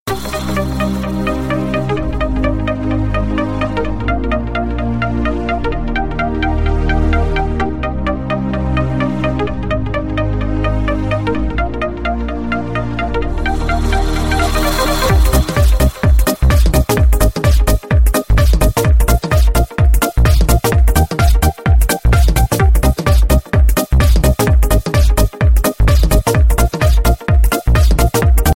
알림음